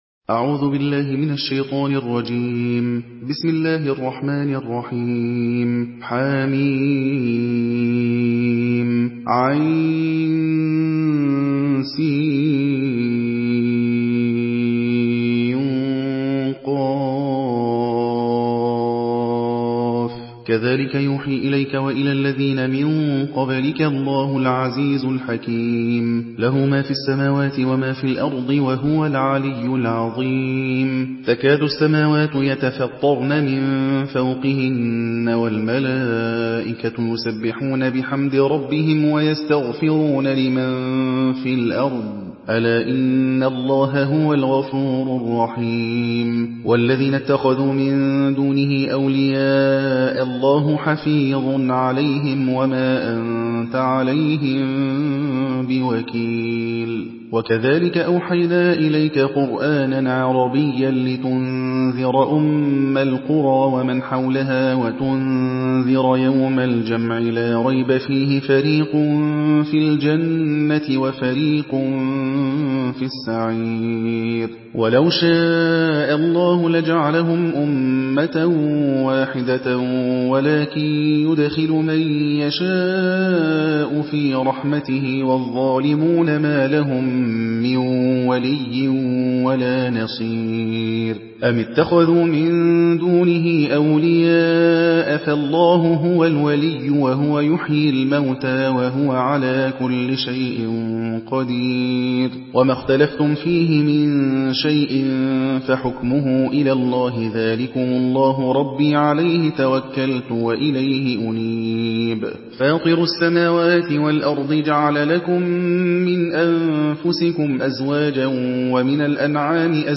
Une récitation touchante et belle des versets coraniques par la narration Hafs An Asim.